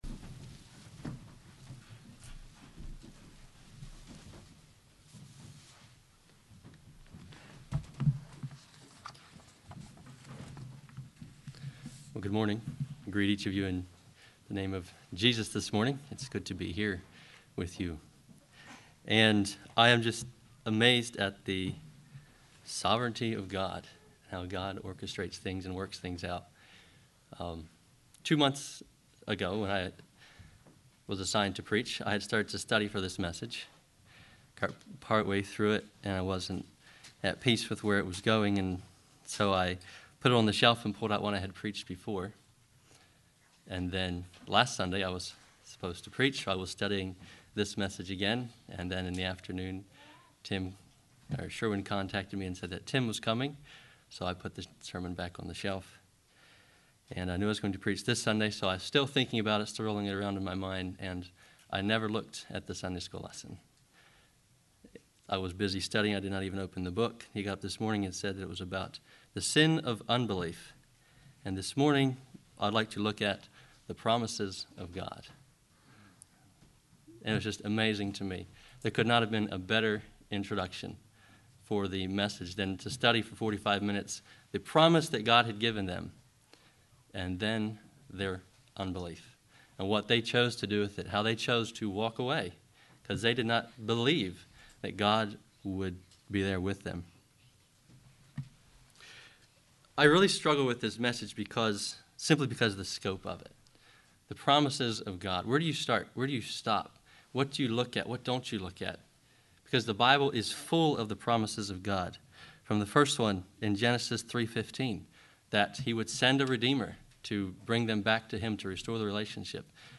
Sermon
See the attached list of God's promises that this congregation read aloud during this sermon.